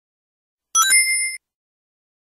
sonic-ring-sound-effect-in-stereo_slrDlrk.mp3